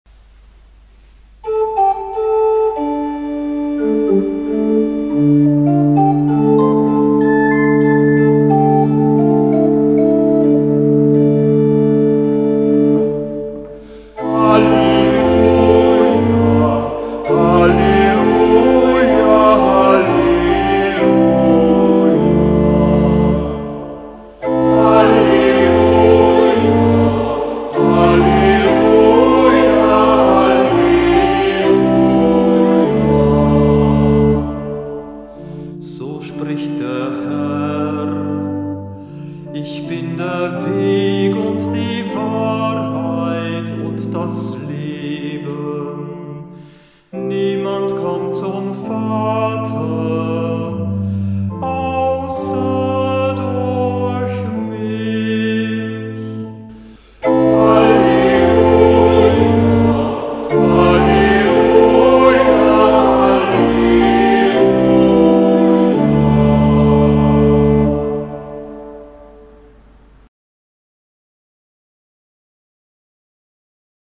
Kantor der Verse